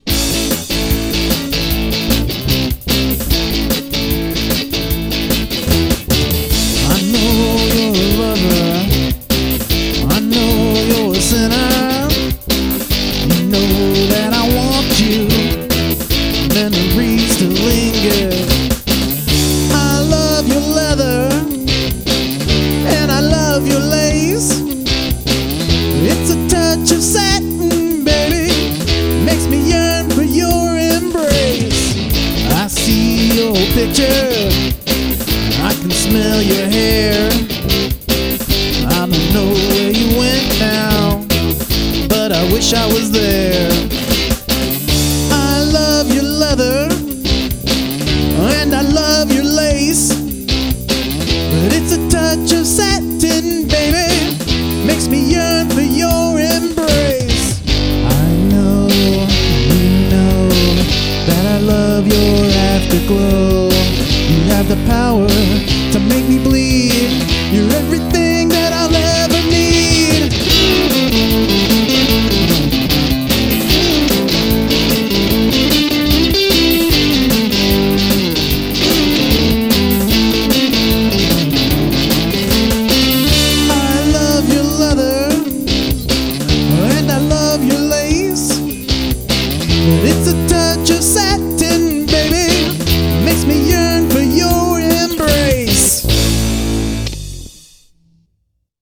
A title is posted and we have one hour to write and record the demo. I don't have my condenser so the vocals are through a sm58 which does *not* suit my voice at all.
And I'm really enjoying rocking out a little more.
The kinda of performance that I'd be having to shout over to order my beer.
Leaving the timescales aside, it's a good high energy song.
I really like the rocking rhythm you have going on here.
Sung with raw emotion.
That chorus is killer, and the solo licks are pretty freakin' cool!